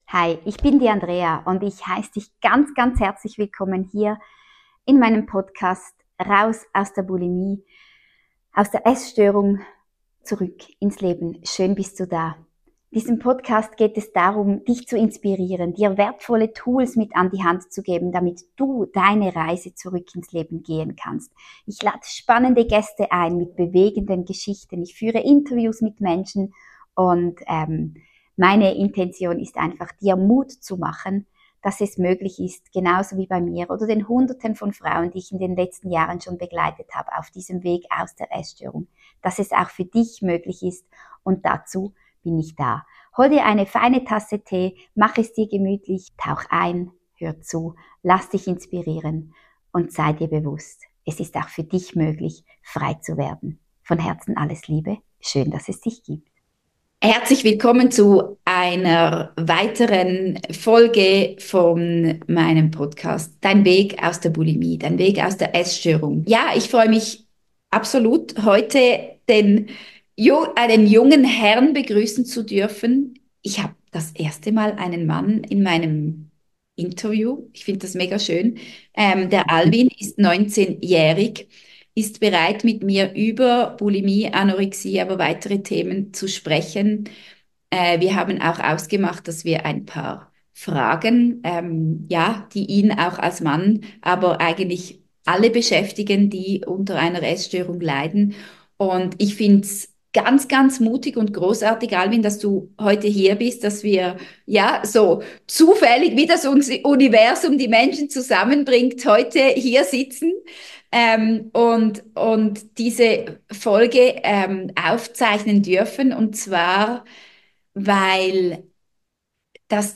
Teil 2 dieses Gesprächs folgt am 28.11.24 also seid gespannt und ich wünsche euch ganz viel Spaß bei zuhören.